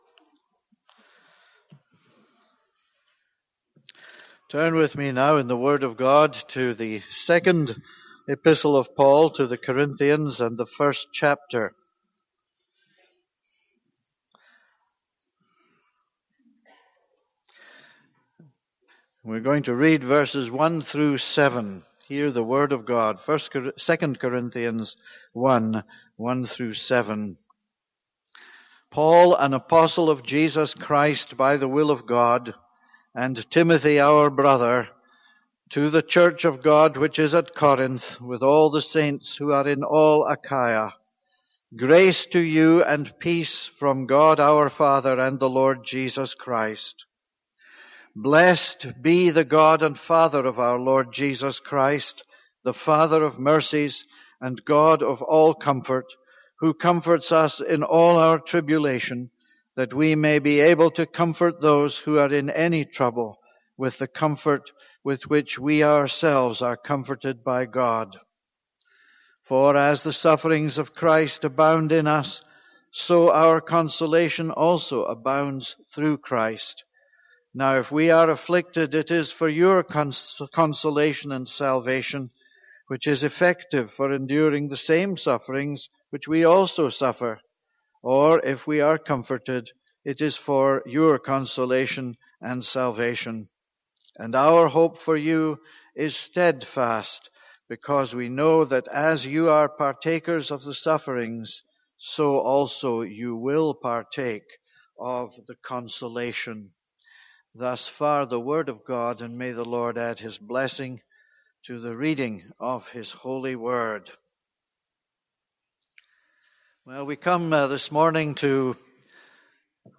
This is a sermon on 2 Corinthians 1:1-7.